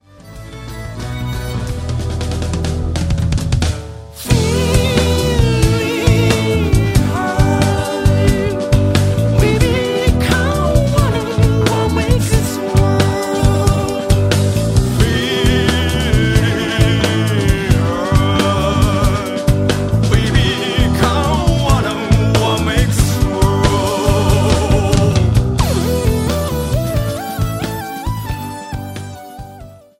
Segment Progressive
Rock/Pops
Symphonic
Violin Future
Vocal Future
Stringed Instrument